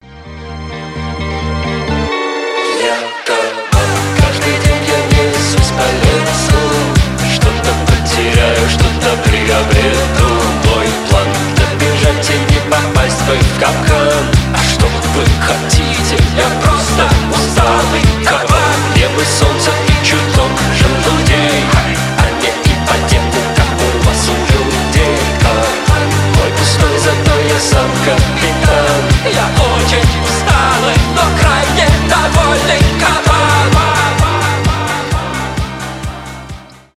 психоделический рок , альтернатива , рок
инди